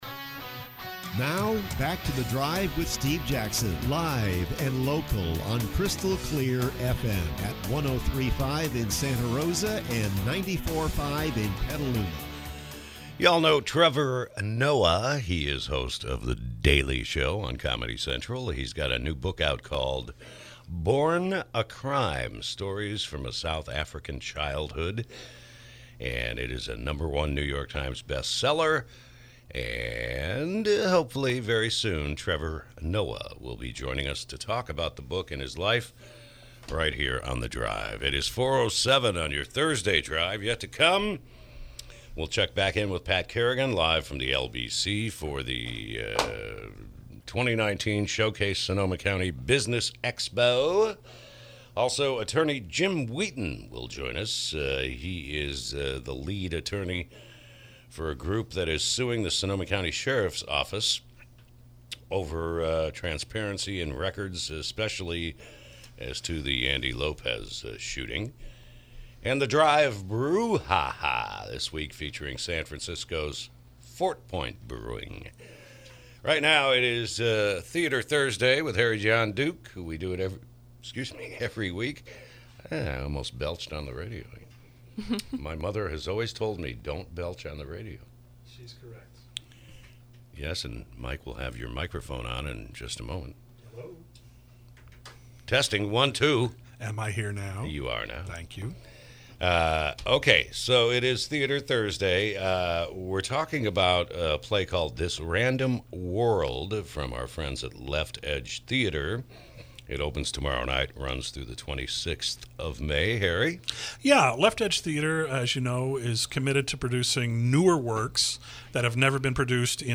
KSRO Interview: “This Random World”